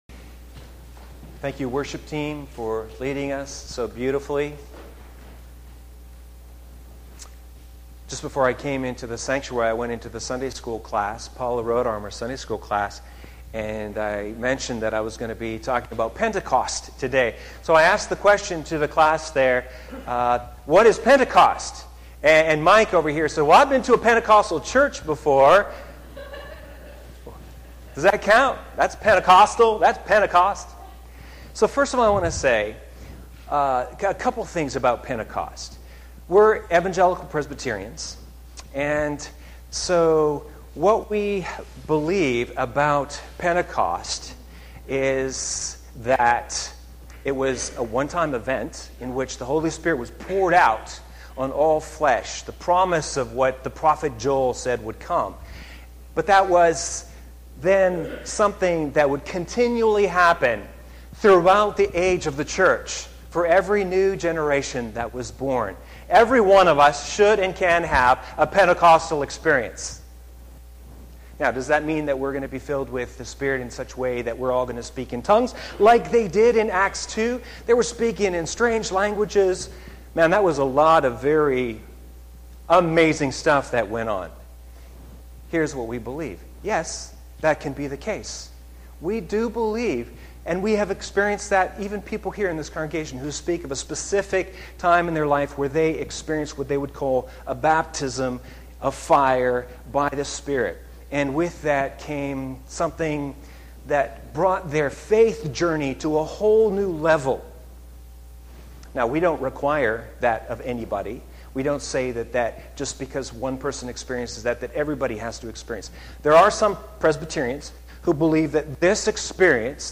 Sermon 9-3-2017
Sermon_9-3-17_Feast_Fire_and_Freedom.mp3